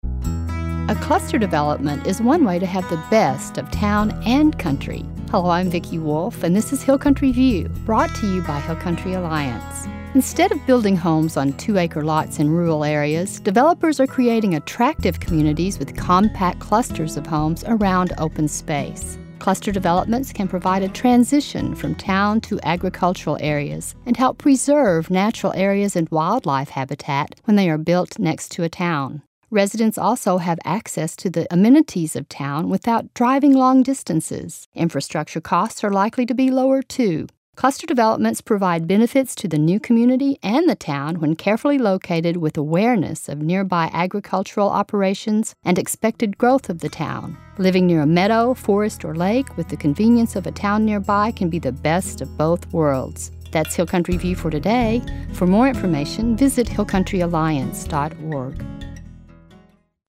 60 Second Radio Spots – 2012